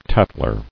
[tat·tler]